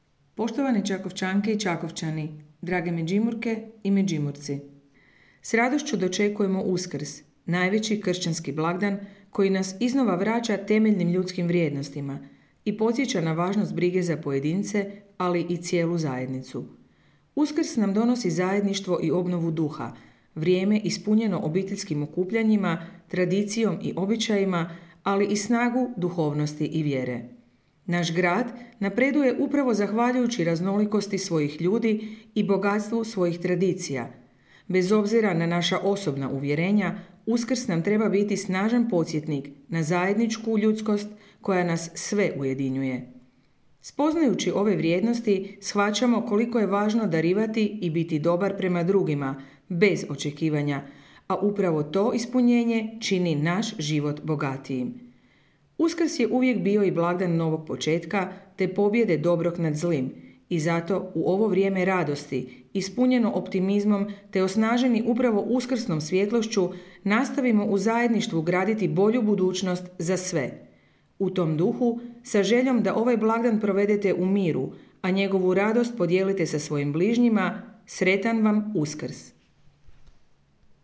Čestitka gradonačelnice Grada Čakovca Ljerke Cividini povodom blagdana Uskrsa - Grad Čakovec
Uz blagdan Uskrsa gradonačelnica Grada Čakovca Ljerka Cividini uputila je čestitku u kojoj stoji:
Uskrsna-cestitka-gradonacelnica-Ljerka-Cividini.mp3